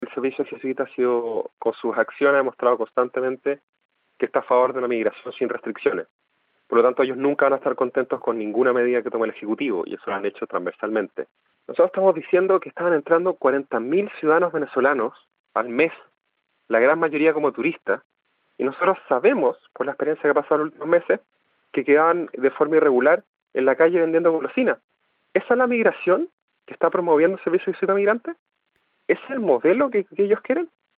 En radio Pauta, el Jefe de departamento de extranjería y Migración, Álvaro Bellolio, cuestionó "la migración que está promoviendo el Servicio Jesuita a Migrantes (SJM)".